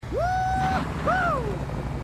Halo Dialogue Snippets
woo-hoo_stack.mp3